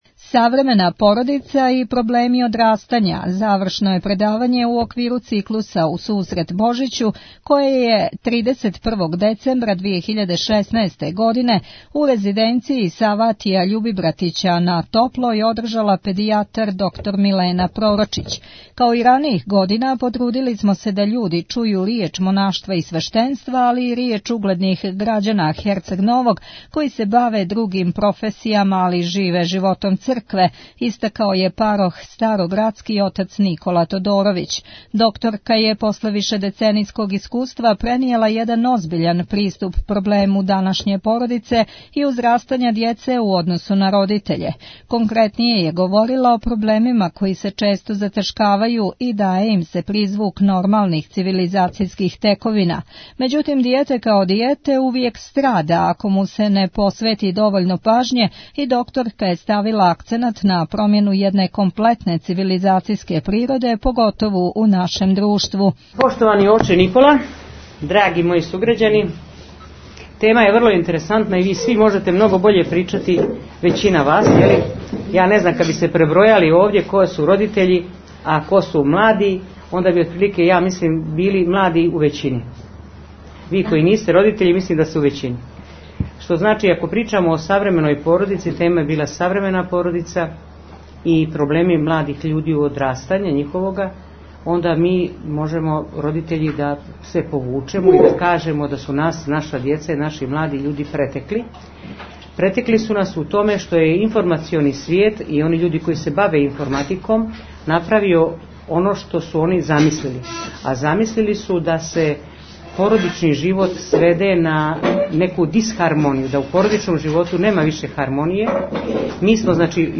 Tagged: Предавања